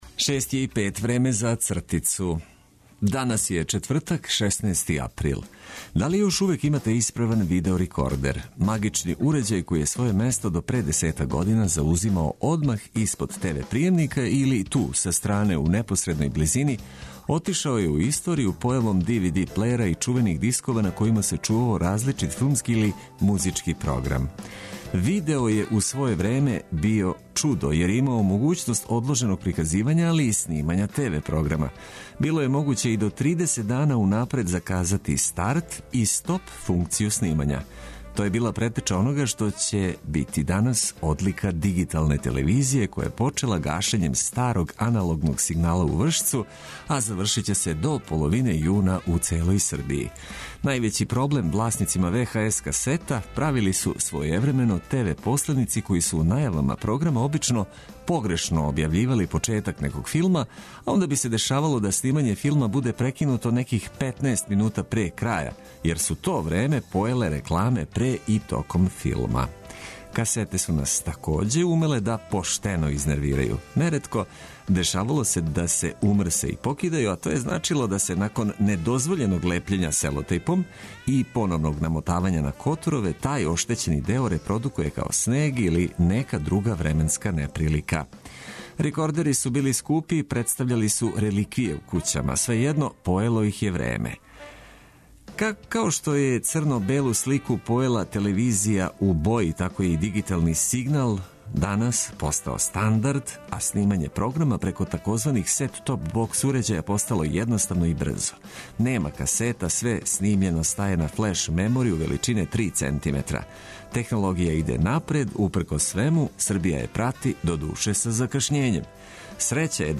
Започнимо дан уз осмех, лепе ноте, добро расположени и ведри упркос свему.